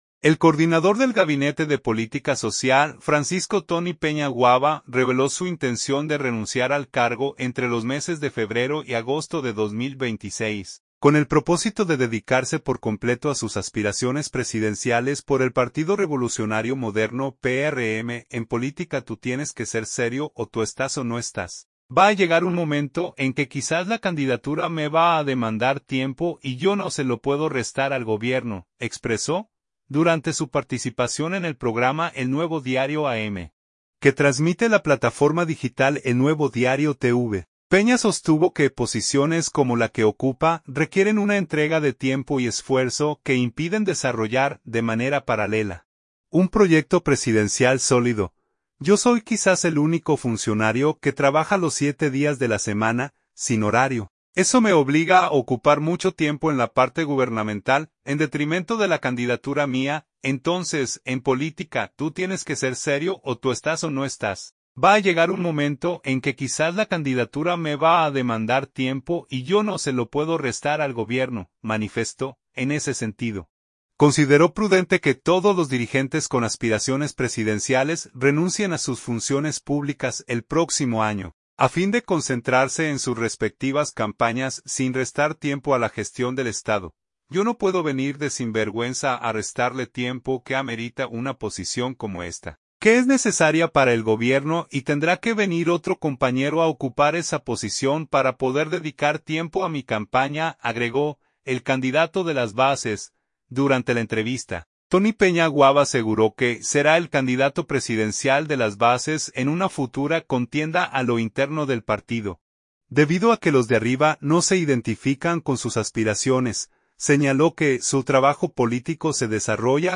Durante su participación en el programa “El Nuevo Diario AM”, que transmite la plataforma digital El Nuevo Diario TV, Peña sostuvo que posiciones como la que ocupa requieren una entrega de tiempo y esfuerzo que impiden desarrollar, de manera paralela, un proyecto presidencial sólido.